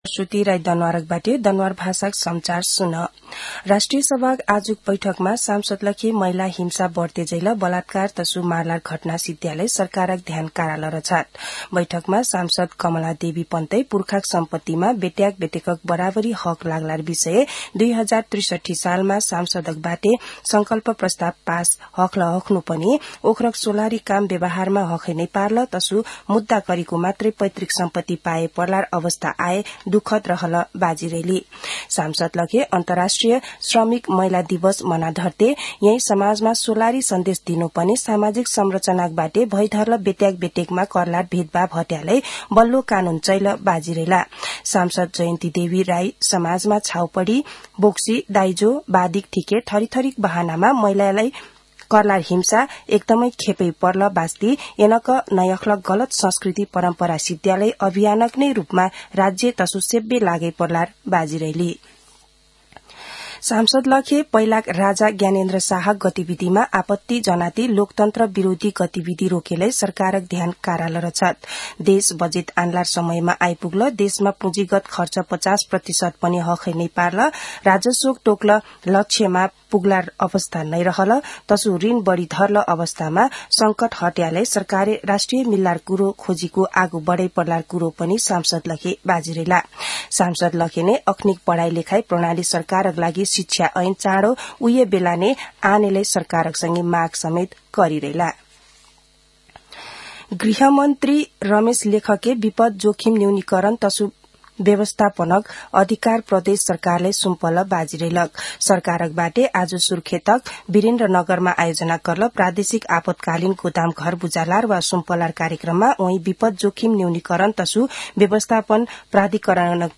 दनुवार भाषामा समाचार : २६ फागुन , २०८१
Danwar-News-25.mp3